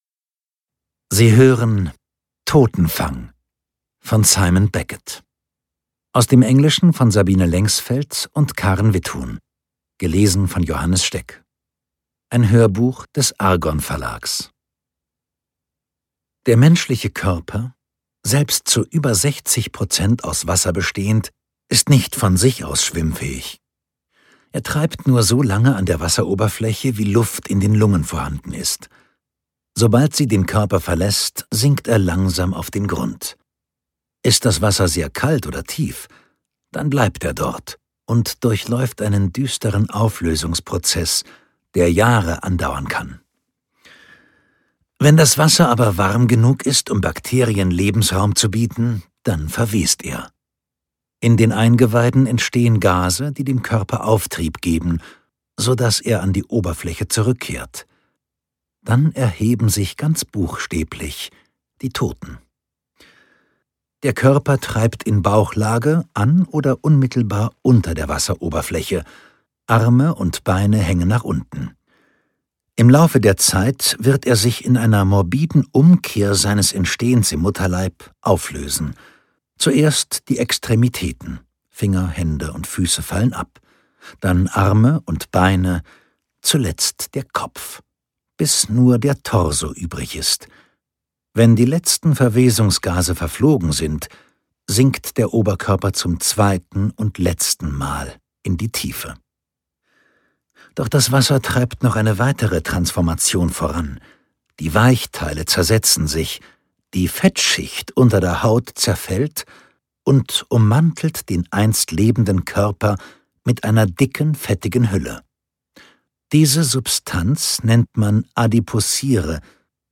Seine ausdrucksstarke, tiefe Stimme mit dem rauen Timbre zieht jeden Hörer und jede Hörerin in ihren Bann.